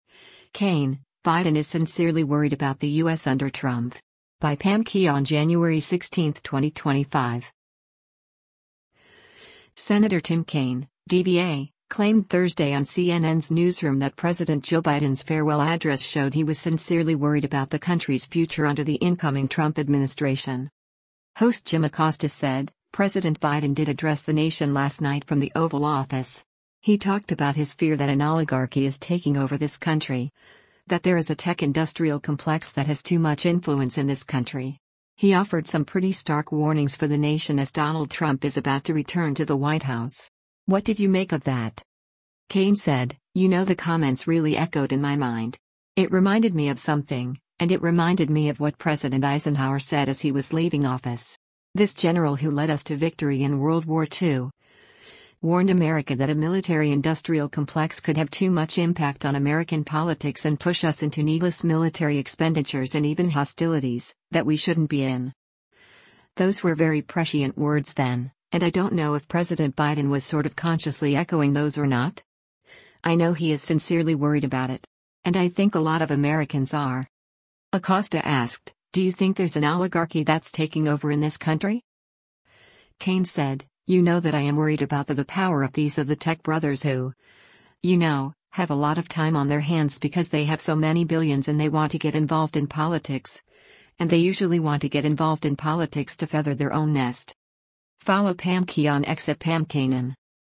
Senator Tim Kaine (D-VA) claimed Thursday on CNN’s “Newsroom” that President Joe Biden’s farewell address showed he was “sincerely worried” about the country’s future under the incoming Trump administration.